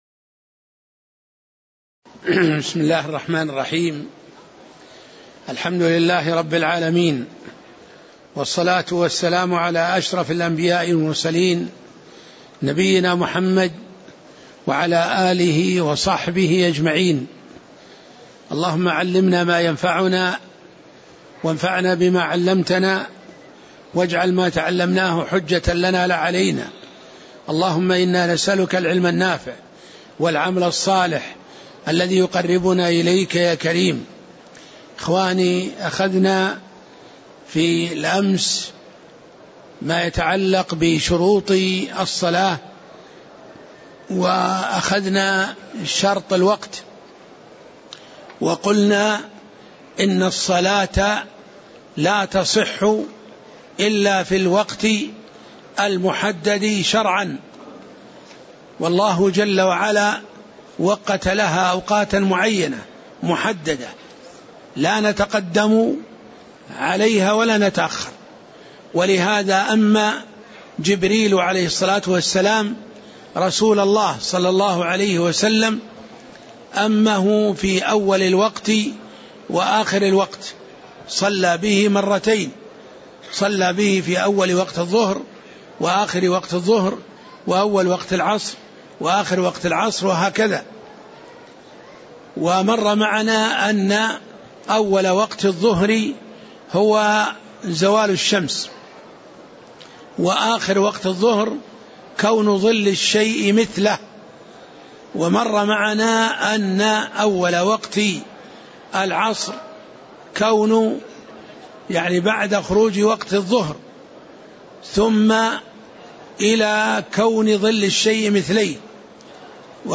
تاريخ النشر ١ جمادى الأولى ١٤٣٨ هـ المكان: المسجد النبوي الشيخ